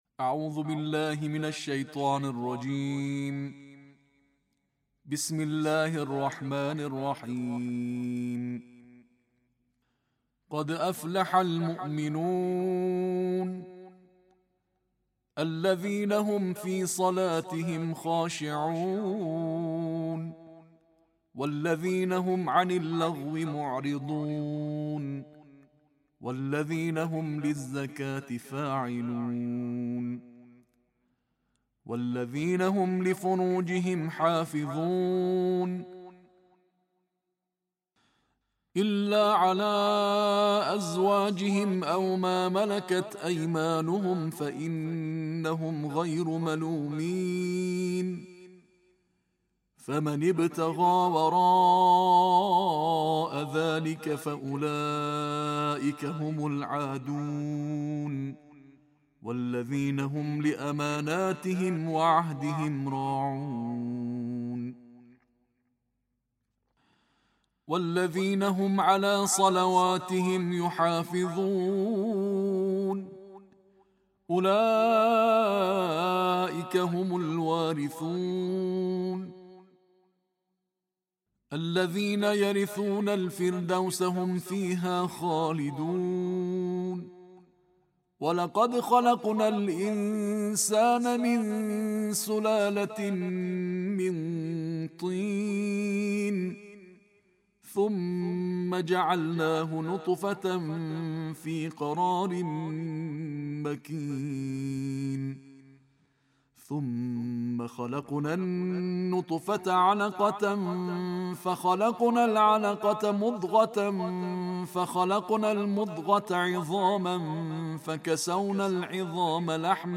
Todos os dias com o Alcorão: Recitação Tarteel de Juz 18